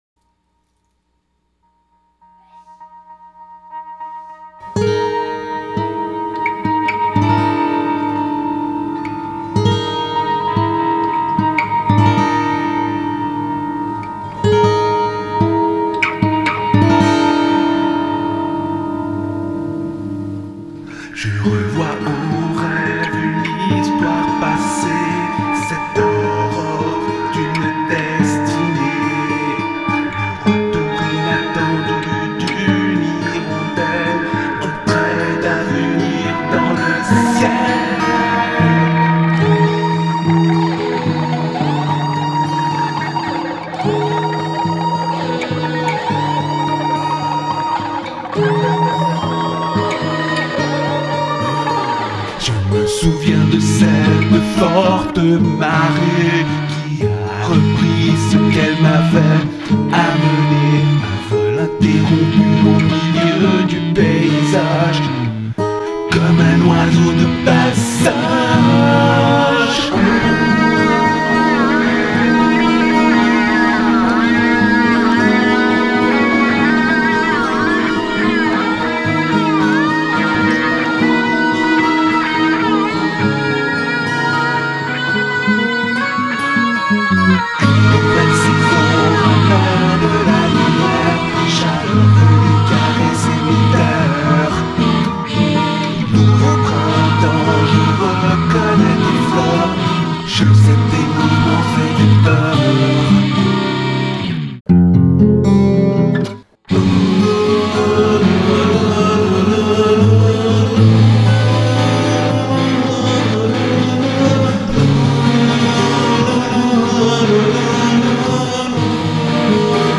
Como sempre, recomendo ouvir em estrutura que evidencie a distribuição estéreo (dita “panorâmica”), pois invisto bastante tempo de estúdio distribuindo os sons no espaço sonoro.
O trabalho foi gravado e mixado através do Reaper, um grande representante das grande família dos DAW (Digital Audio Workstation, estúdios digitais de gravação e mixagem) que, ao contrário de seus grandes concorrentes (ProTools, Nuendo,…) não custa 5 meses dos meus rendimentos e é um excelente trabalho de desenvolvedor.
Lá para 4:00 da música, dei ao violão uma tarefa tradicionalmente atribuída à guitarra elétrica, de enunciar um rife de metal sobre o qual se construiu o solo (estas sim, duas guitarras). A entrada deste violão é bem visível –já quando as guitarras entram também, é preciso prestar atenção para perceber que o tal rife continua. Pessoalmente gostei do resultado, embora isto dê uma impressão bem seca à base, em contraposição ao super brilho dos solos (muita distorção).